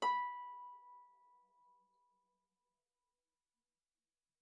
KSHarp_B5_mf.wav